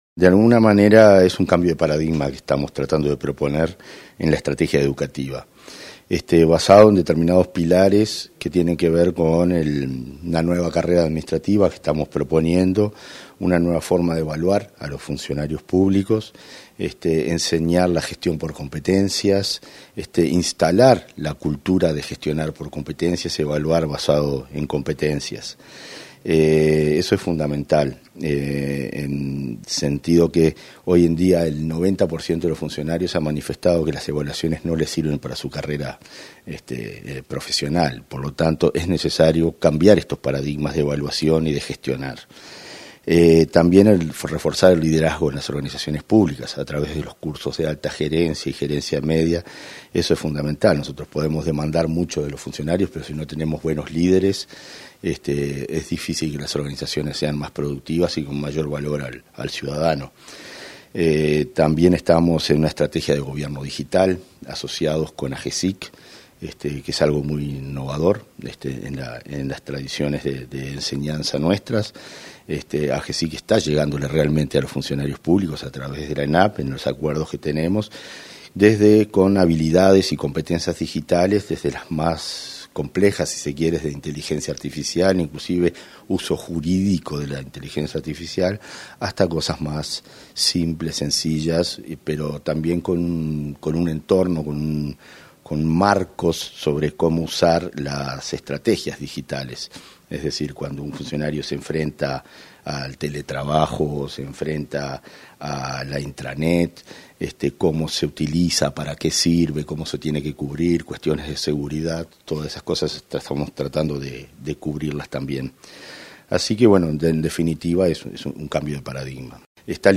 Entrevista al director de la Organización Nacional de Servicio Civil, Conrado Ramos